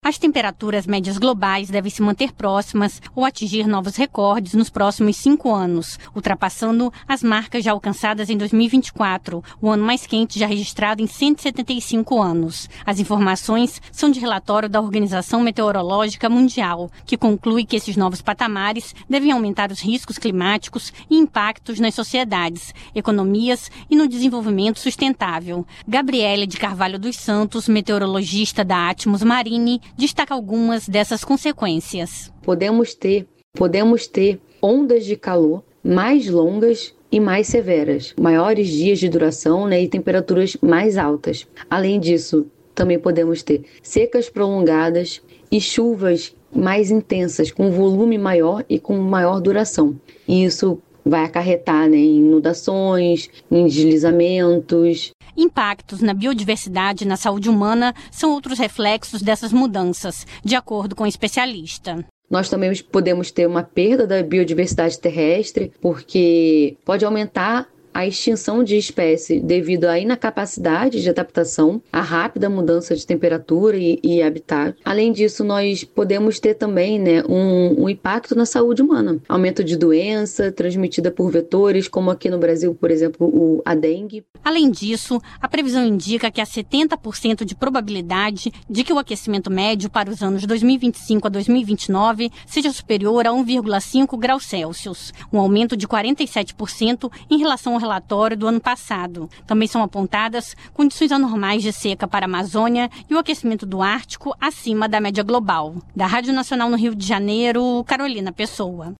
Psicólogo orienta alunos a como controlar o emocional